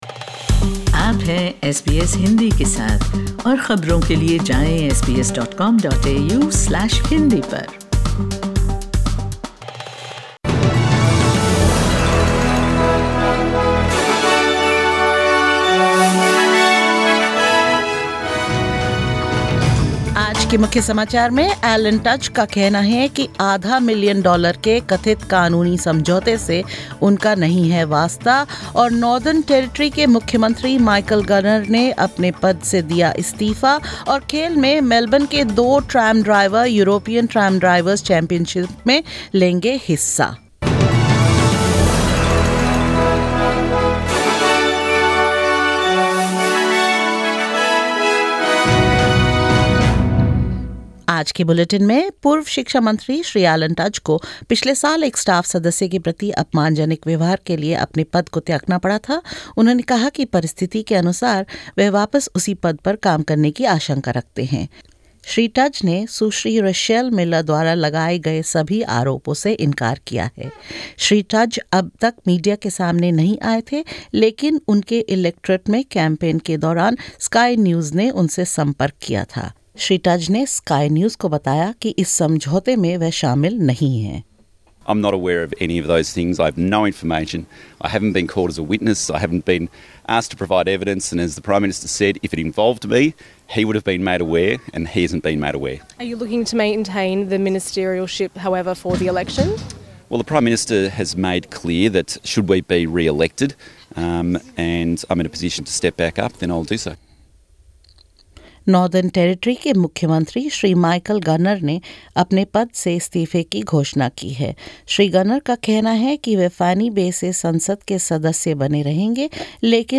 In this latest SBS Hindi bulletin: Alan Tudge refuses involvement in a reported half-million-dollar legal settlement; Michael Gunner announces his resignation as Chief Minister of the Northern Territory; Two Melbourne tram drivers set to compete in the European Tram Driver’s Championships and more.